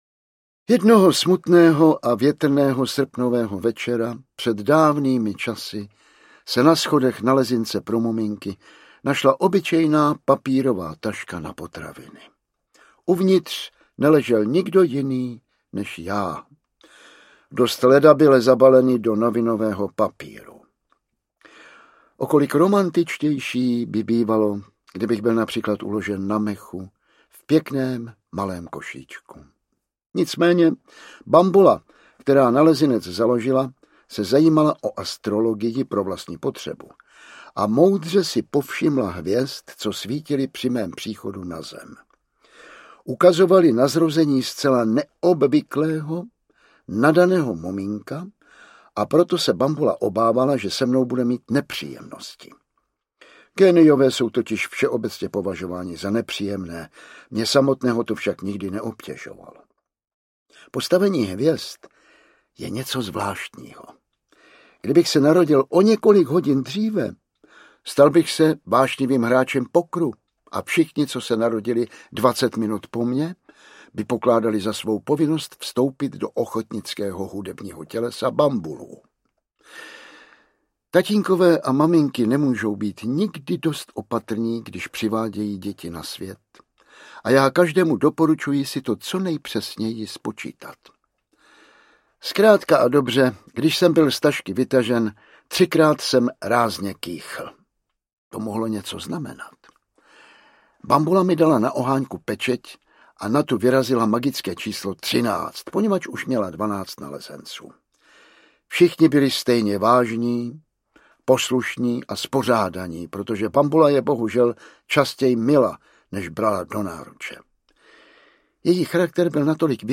Tatínek píše paměti audiokniha
Ukázka z knihy
tatinek-pise-pameti-audiokniha